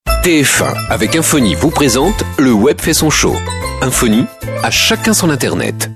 la voix suave